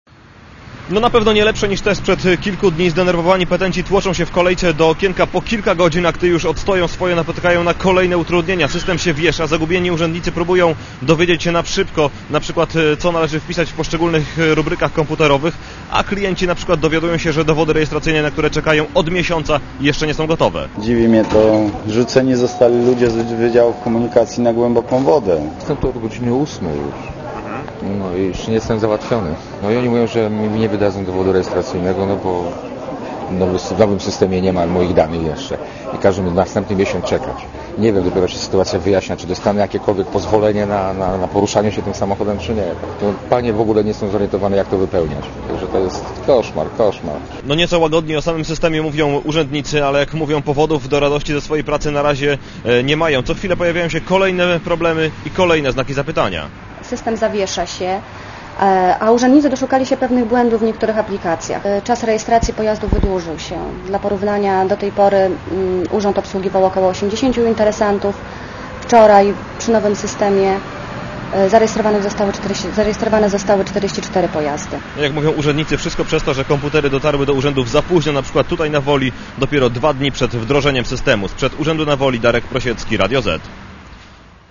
Relacja Radia Zet